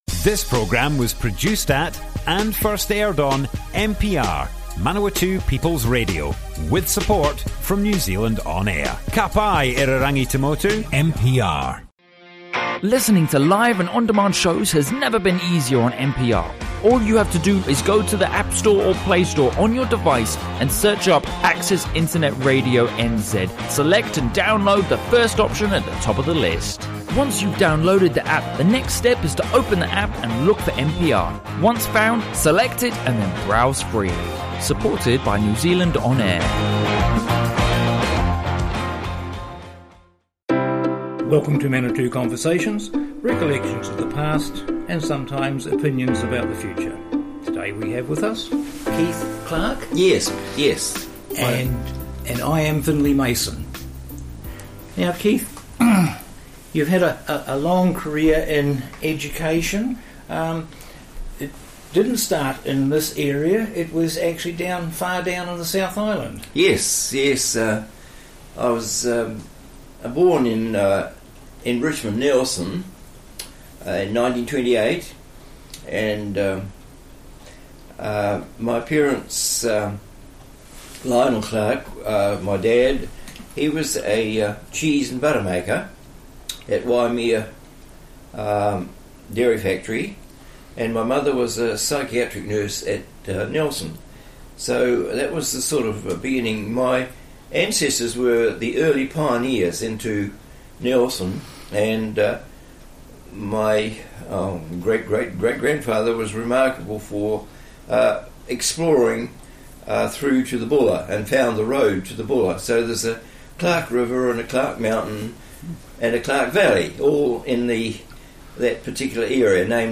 Manawatū Conversations More Info → Description Broadcast on Manawatū People's Radio, 17th September 2019.
oral history